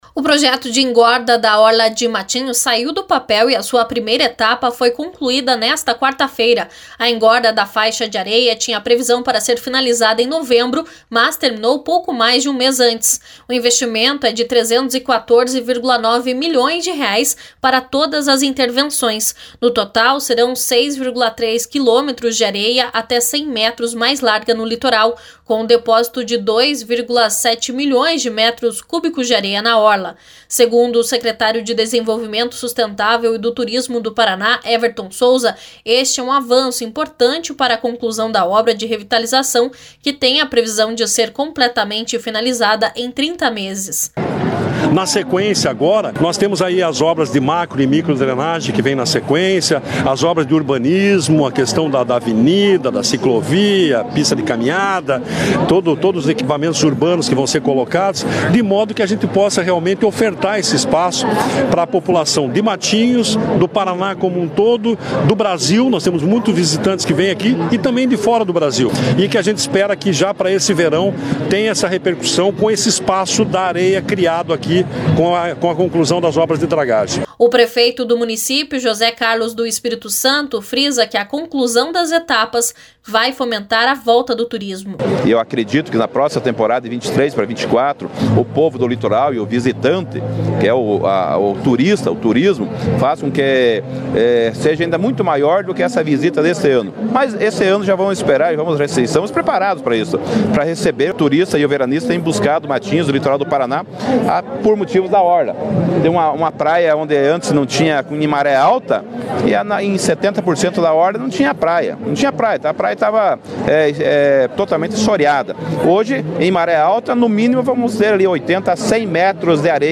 O prefeito do município, José Carlos do Espírito Santo, frisa que a conclusão das etapas vai fomentar a volta do turismo.